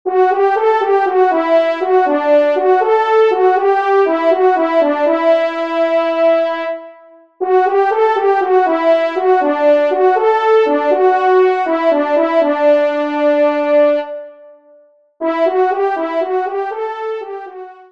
Genre :  Divertissement pour Trompes ou Cors
1ère Trompe